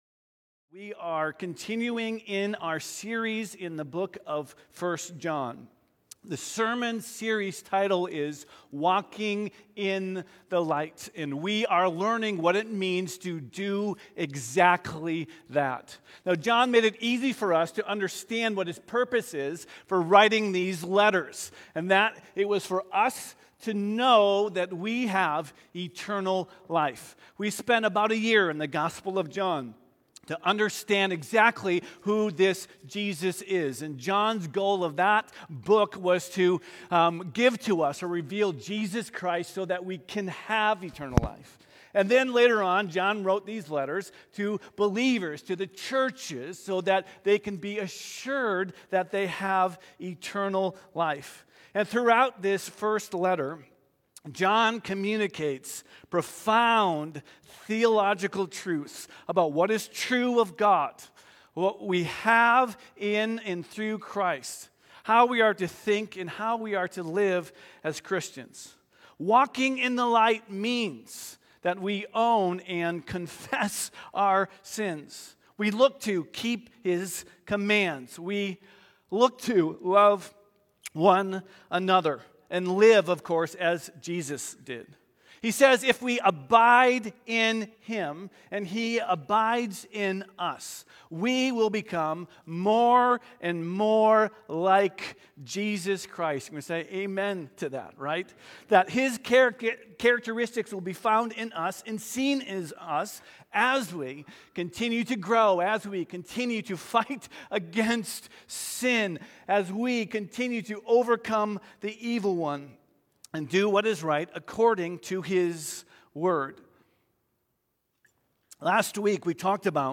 This morning, we are continuing our series through the letters of John as we are learning what it means to “walk in the light.”